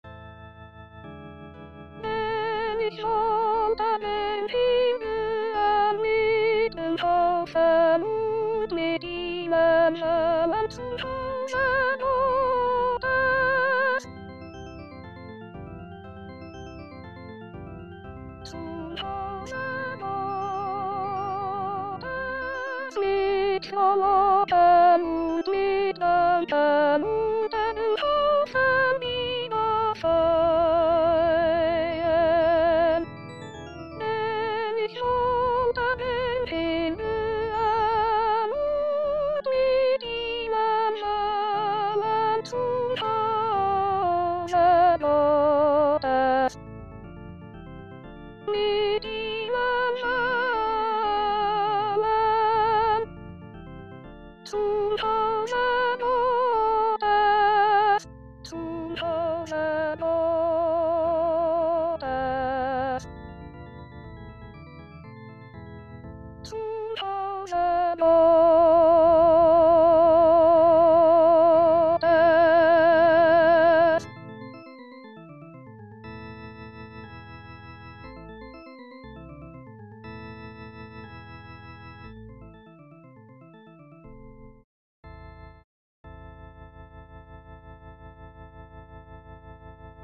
A   Tutti